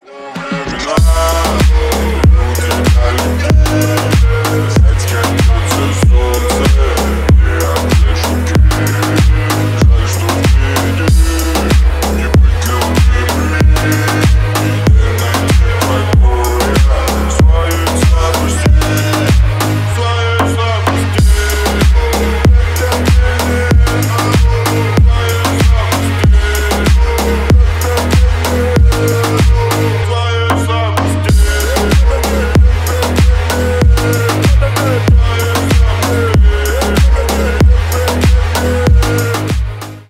басы
ремиксы
рэп , хип-хоп